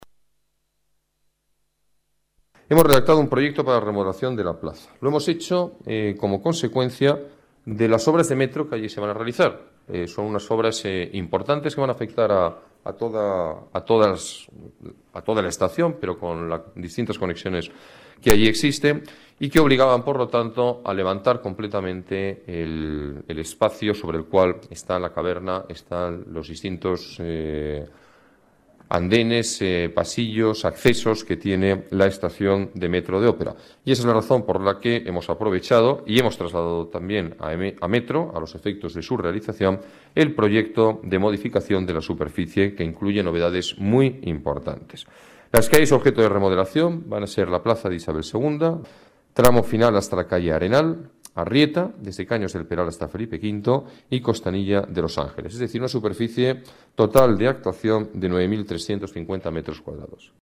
Nueva ventana:Declaraciones del alcalde de Madrid, Alberto Ruiz-Gallardón: remodelación plaza de Isabel II